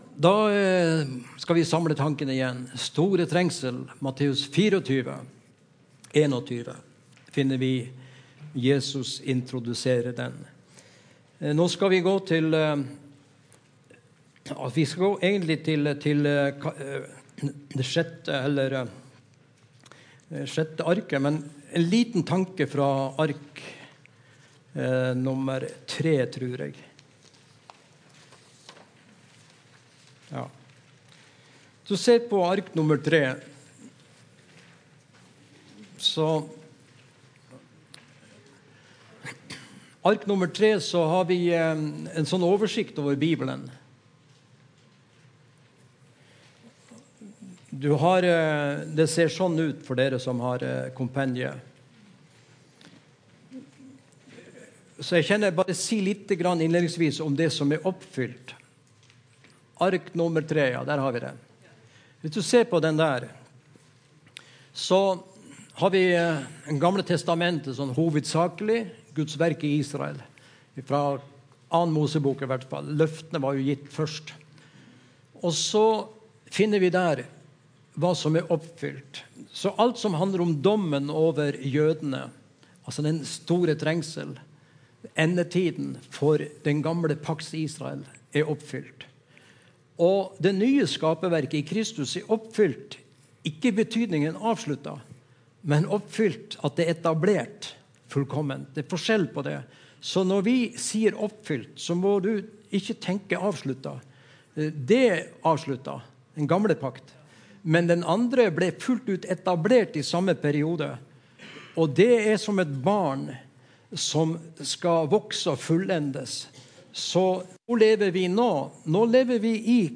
Evangeliehuset Romerike - Gudstjenester